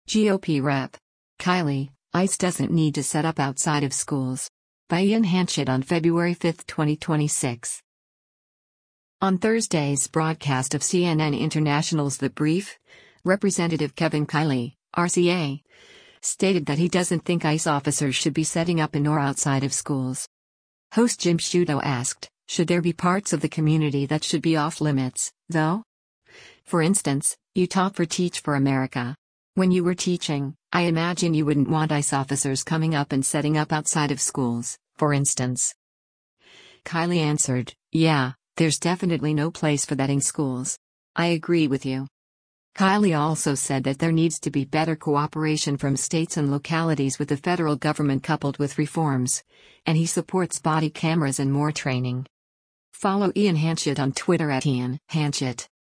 On Thursday’s broadcast of CNN International’s “The Brief,” Rep. Kevin Kiley (R-CA) stated that he doesn’t think ICE officers should be setting up in or outside of schools.